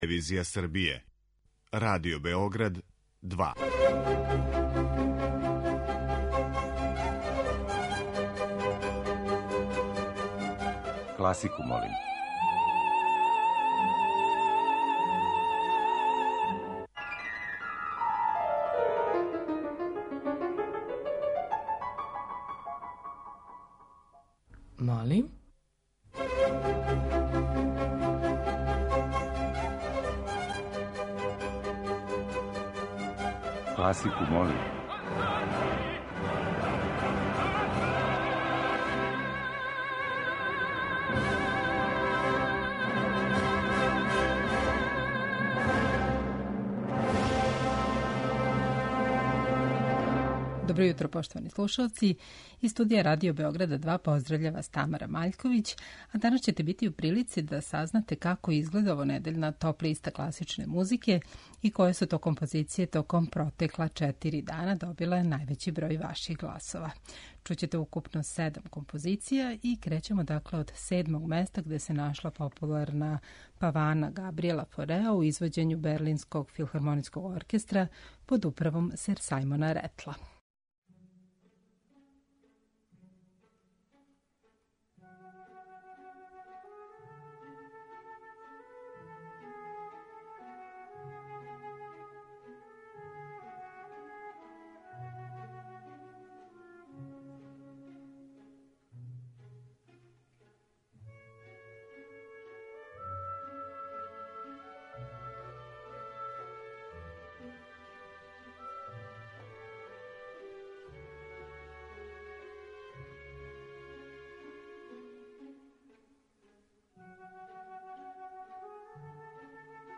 Тема циклуса: серенаде